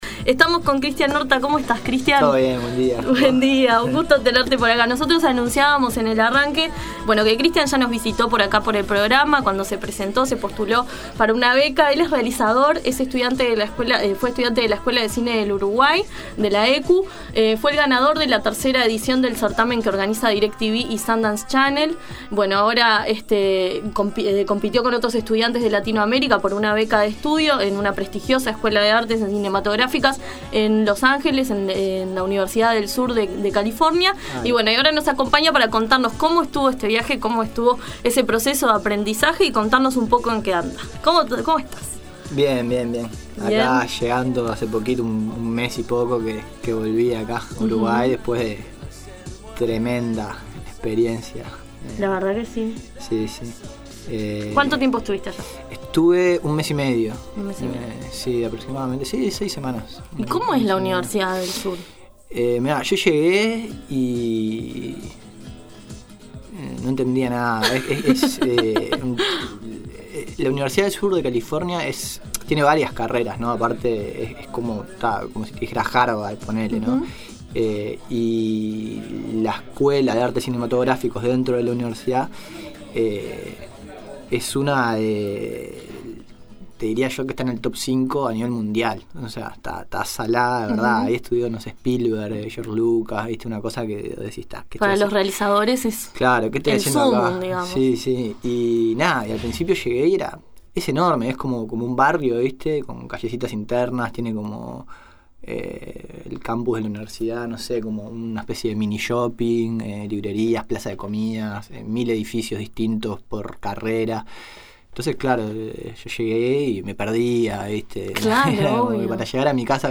*Foto diario El País Escuchá la aquí la entrevista.